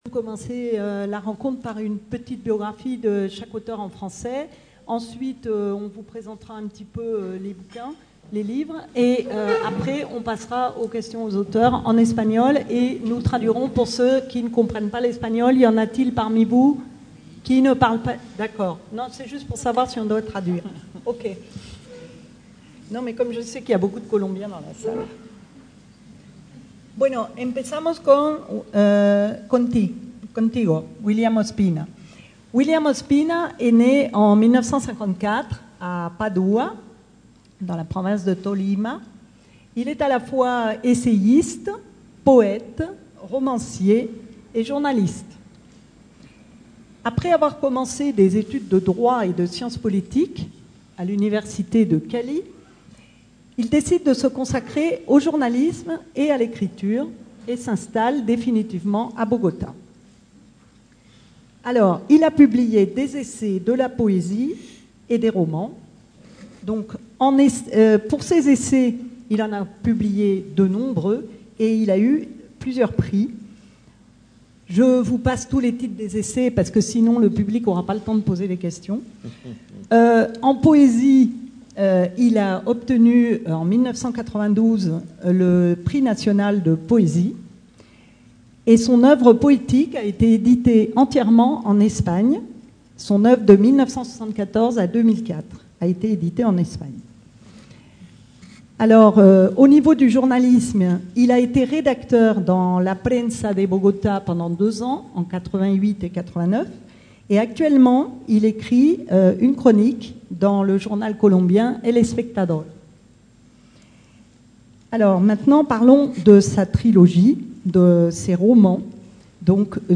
A l'occasion du festival littéraire "Belles Etrangères" organisé par le Centre National du Livre : Rencontre avec les auteurs Evelio Rosero et William Ospina.
Rencontre littéraire